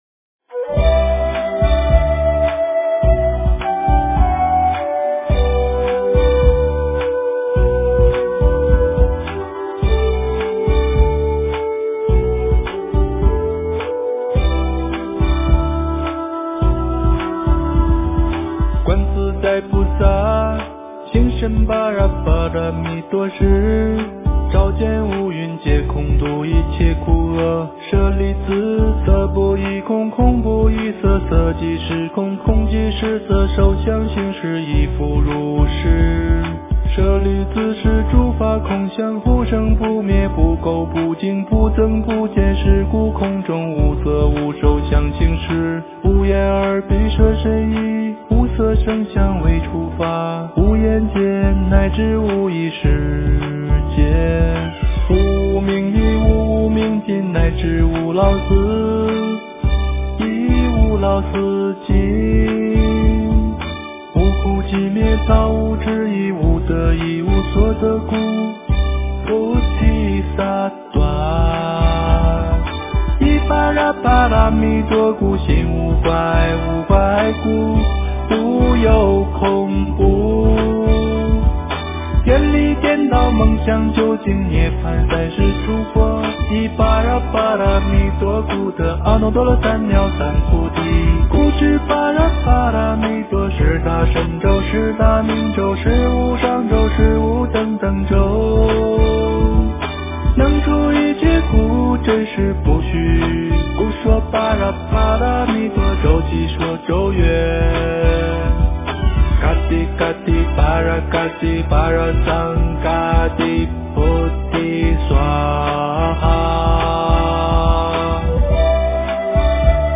诵经
佛音 诵经 佛教音乐 返回列表 上一篇： 大悲咒 下一篇： 般若波罗蜜多心经 相关文章 药师灌顶真言--圆光佛学院法师 药师灌顶真言--圆光佛学院法师...